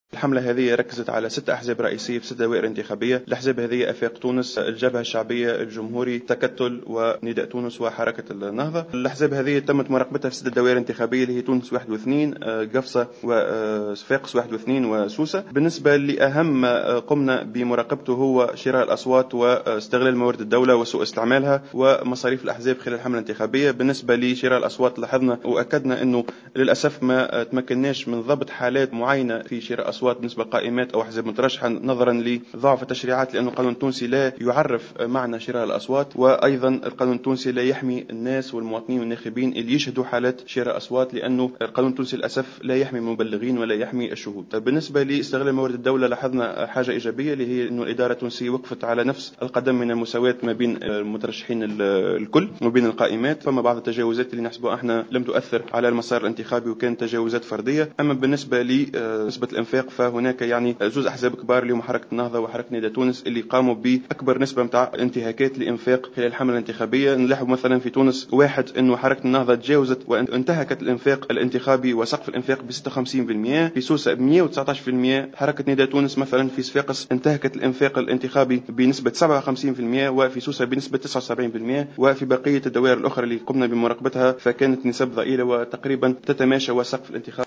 L'organisation « I watch » a organisé une conférence ce lundi 15 décembre 2014 pour exposer ses résultats relatifs au contrôle du financement des campagnes électorales des législatives du 26 octobre.